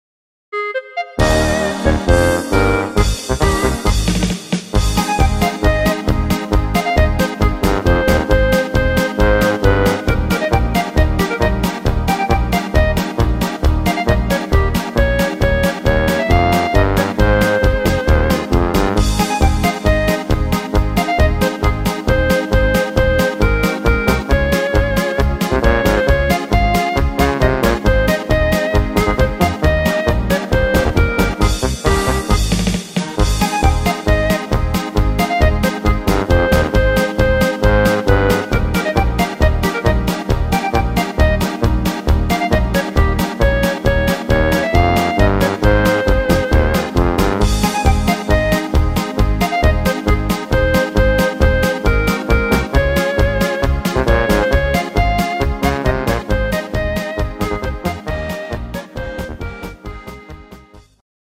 Im Stil von  Oberkrainer
Rhythmus  Polka
Art  Instrumental Allerlei, Volkstümlich